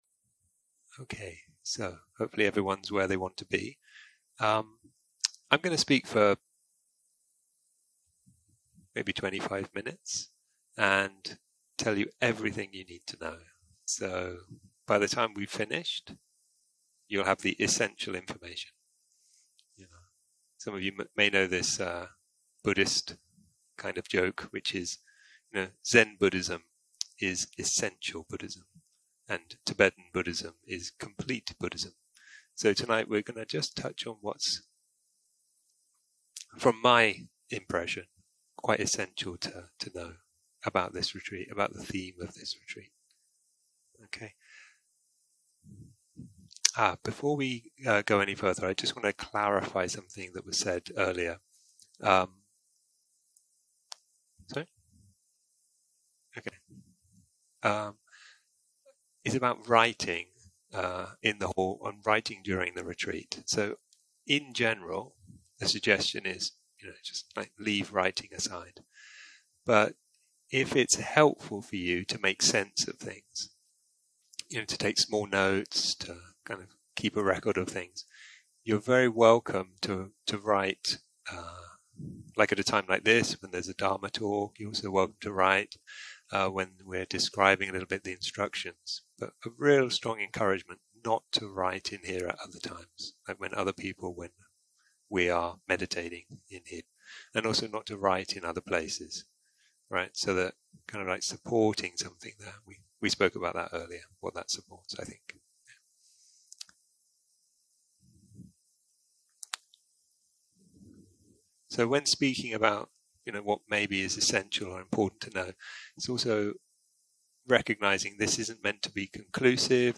יום 1 - הקלטה 2 - ערב - שיחת דהרמה - כל מה שצריך לדעת
סוג ההקלטה: שיחות דהרמה